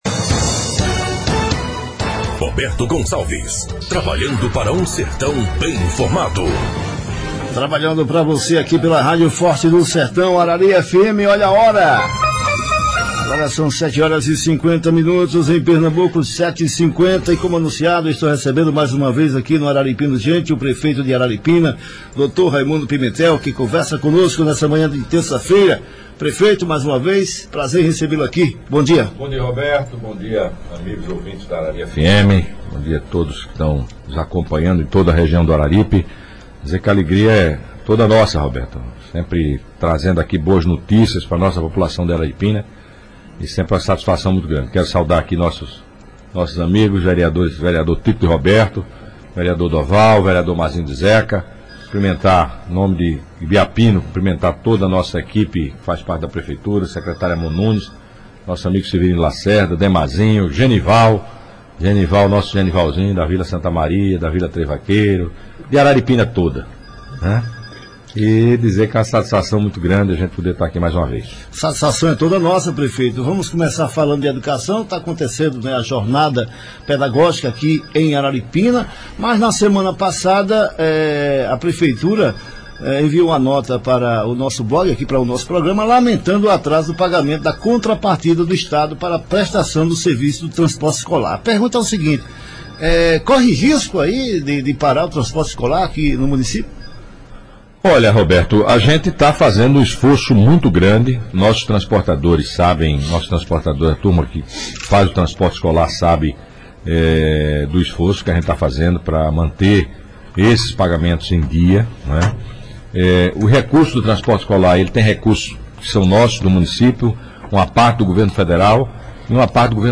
O prefeito de Araripina Raimundo Pimentel (PSL), afirmou nesta terça-feira na Radio Arari FM, que o Governo do Estado não vem cumprindo com o pagamento da contrapartida para prestação do serviço do transporte escolar no município.